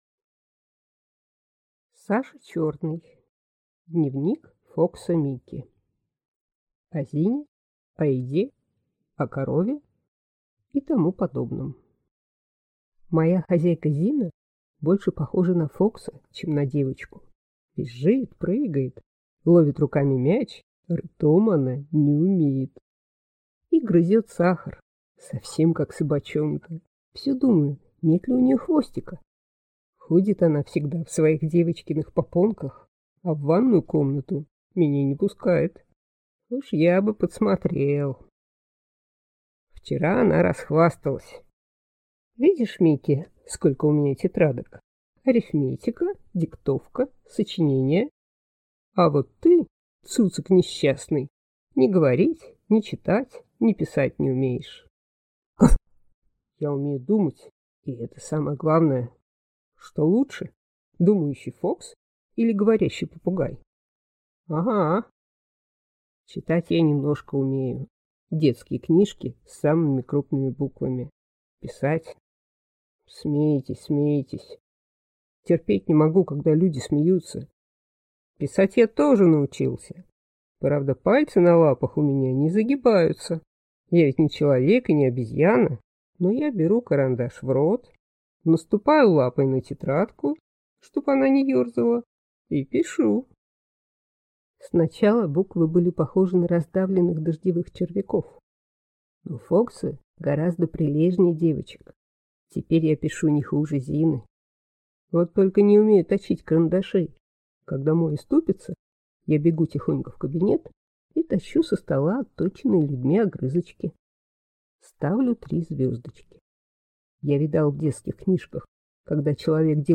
Аудиокнига Дневник Фокса Микки | Библиотека аудиокниг